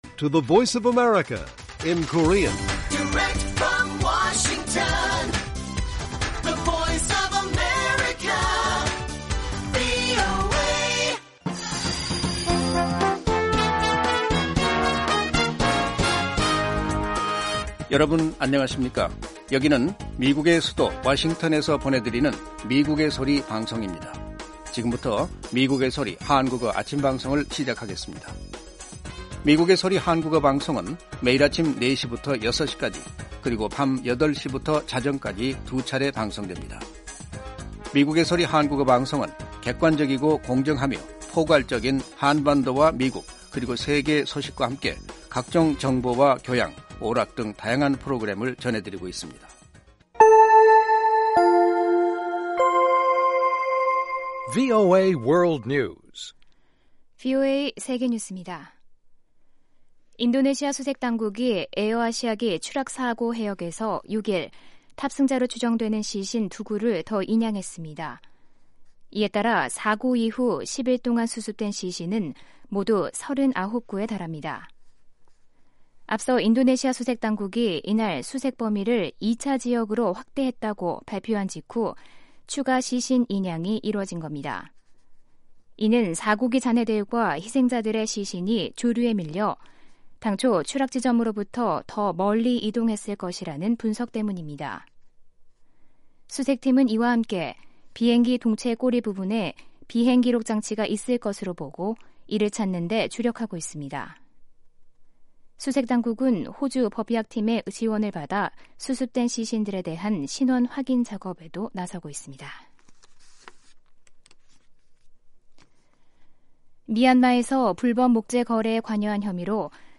VOA 한국어 방송의 아침 뉴스 프로그램 입니다. 한반도 뉴스와 함께 밤 사이 미국과 세계 곳곳에서 일어난 생생한 소식을 빠르고 정확하게 전해드립니다.